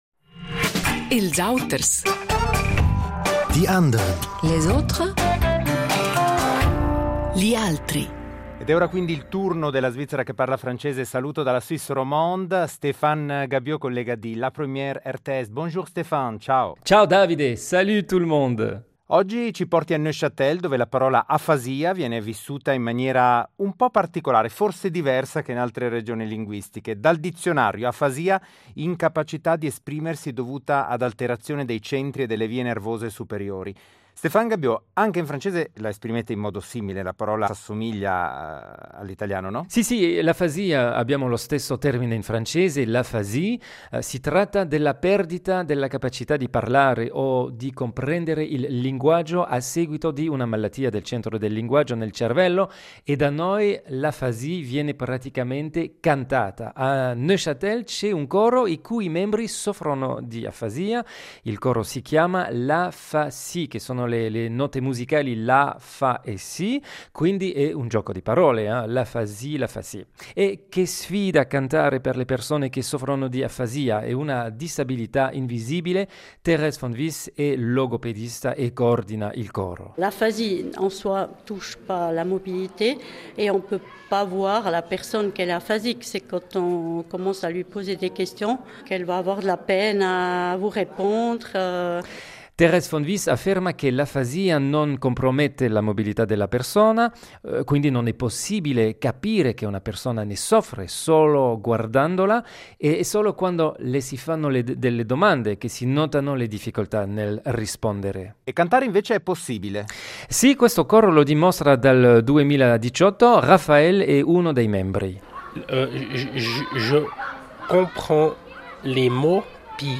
cantare, coro
Dal 2018 il coro La fa si riunisce persone con afasia, trasformando la melodia in terapia. Canzoni semplici e ritmate, scelte con cura, permettono ai partecipanti di esprimersi e sentirsi parte di una comunità.